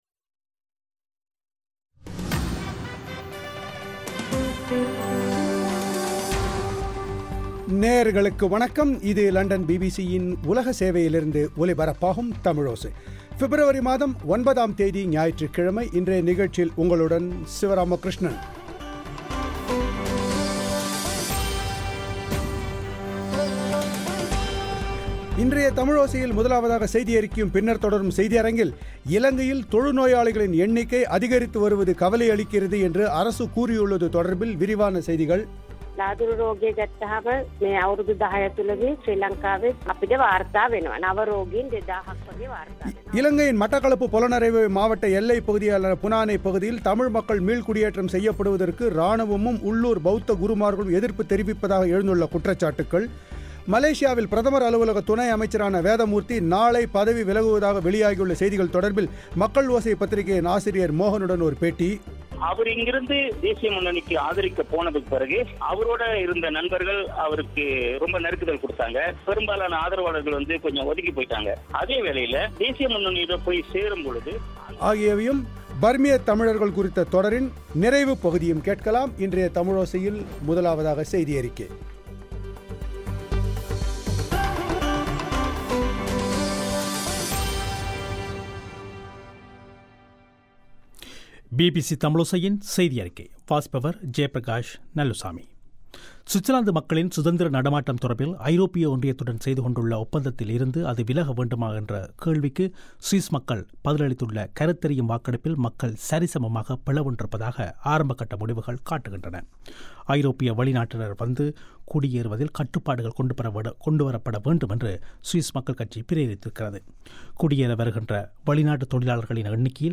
ஒரு பேட்டி